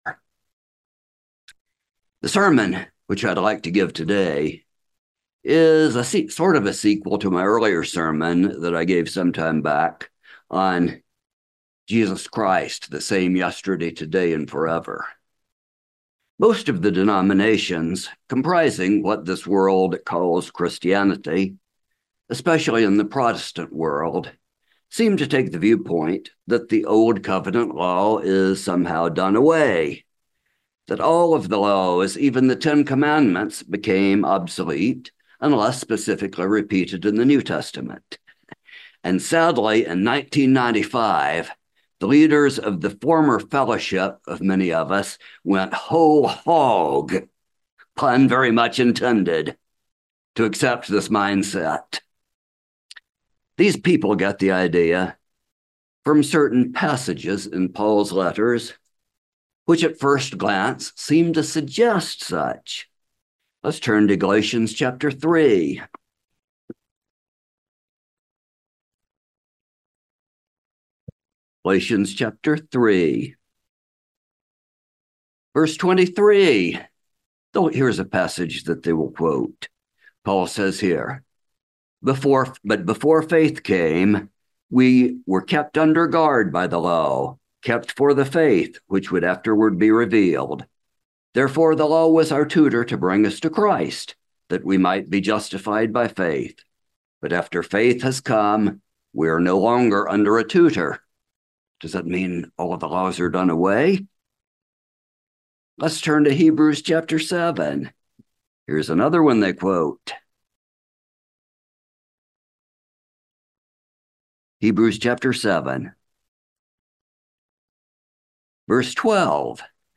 Given in Kingsport on Sabbath, May 21, 2022